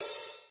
hihat-open.wav